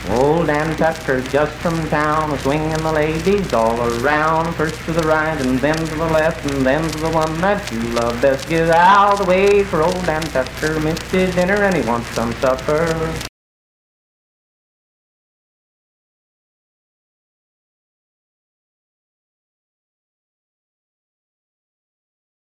Unaccompanied vocal performance
Dance, Game, and Party Songs
Voice (sung)
Roane County (W. Va.), Spencer (W. Va.)